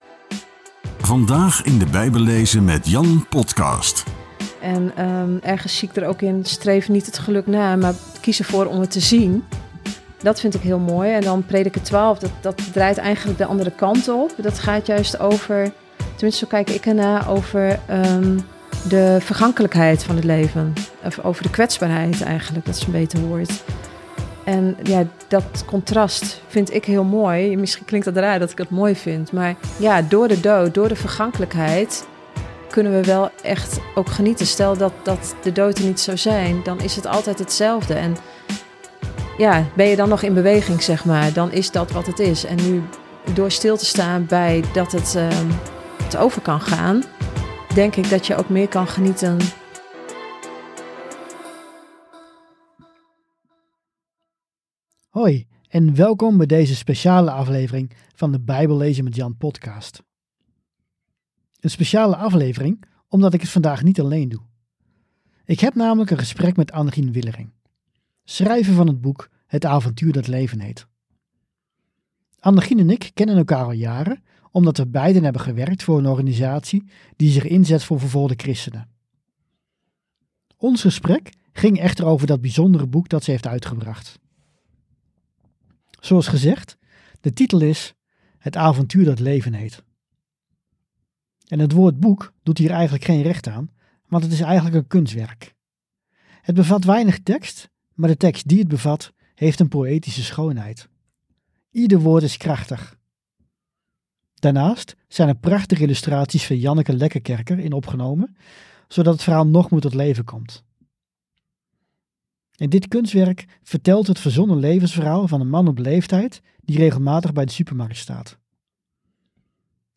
Een speciale aflevering omdat ik het vandaag niet alleen doe.